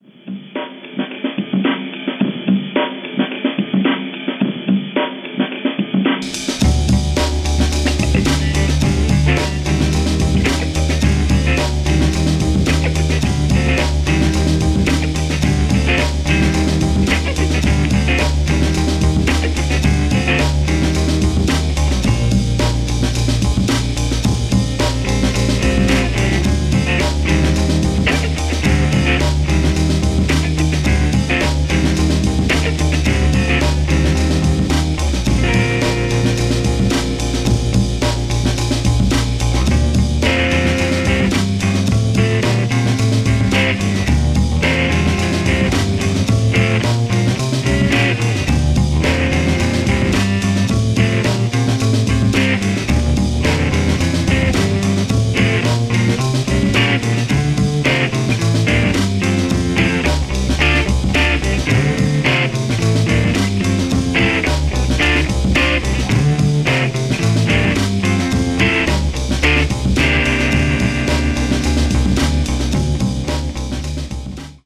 (1:14) Some rock lost in the jungle